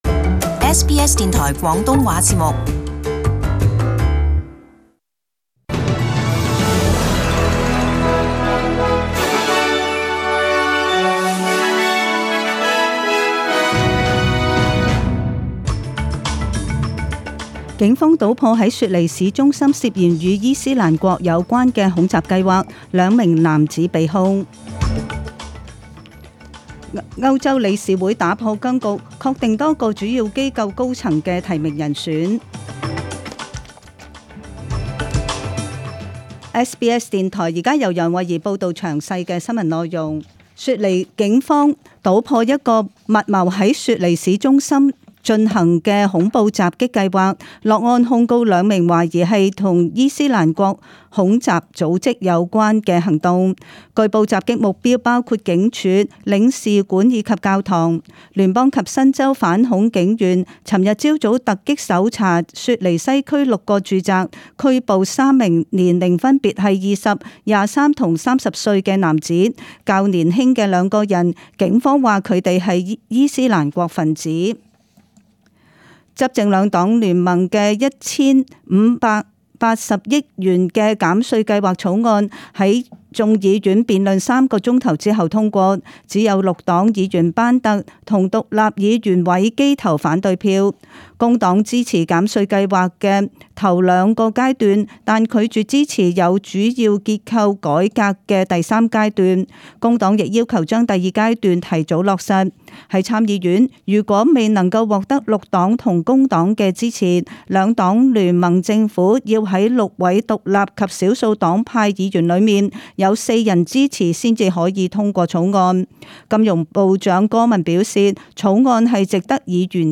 SBS中文新闻 （七月三日）
请收听本台为大家准备的详尽早晨新闻。